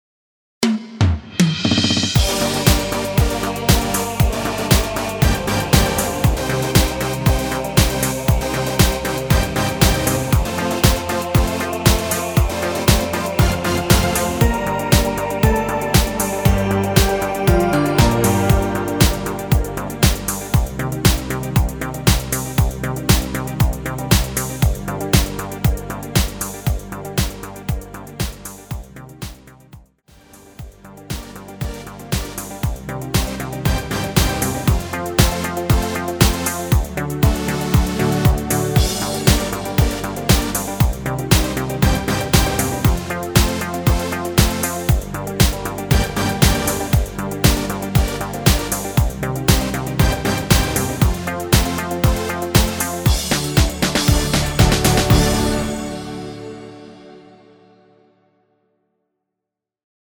엔딩이 페이드 아웃이라 엔딩을 만들어 놓았습니다.(미리듣기 참조)
Gm
◈ 곡명 옆 (-1)은 반음 내림, (+1)은 반음 올림 입니다.
앞부분30초, 뒷부분30초씩 편집해서 올려 드리고 있습니다.